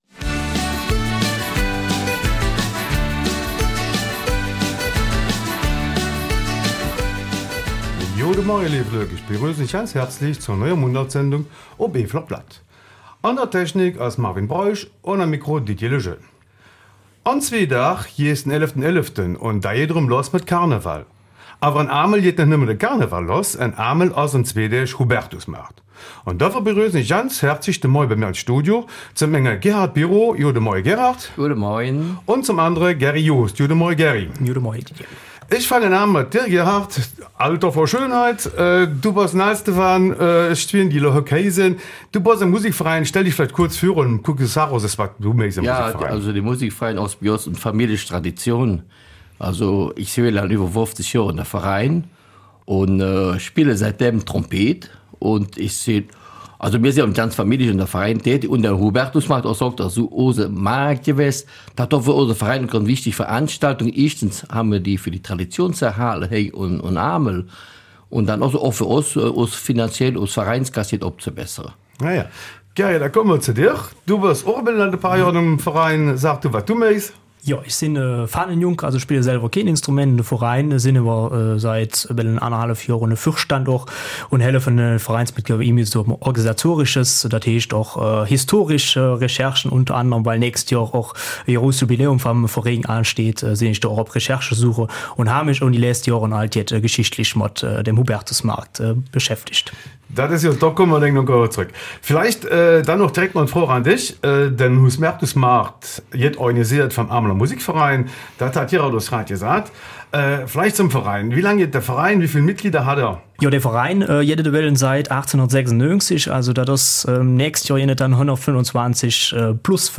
Eifeler Mundart - 9. November